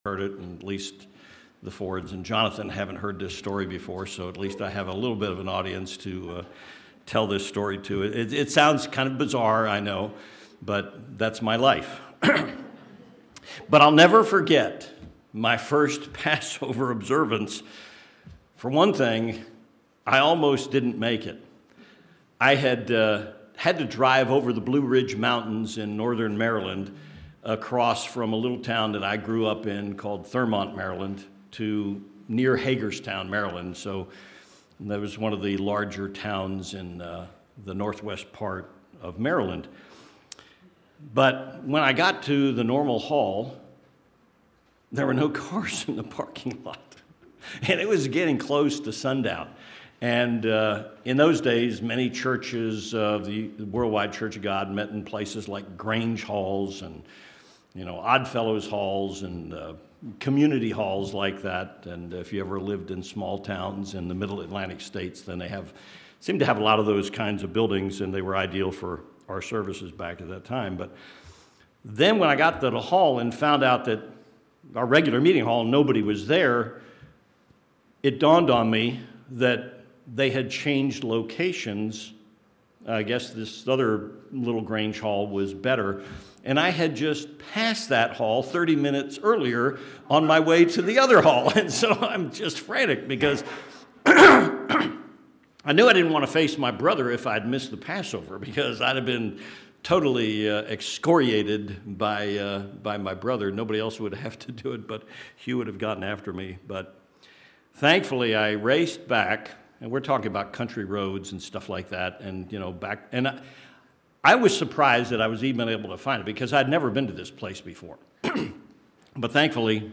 This sermon examines the history of Passover from Exodus 12 and the New Testament Observance as ordained by Jesus.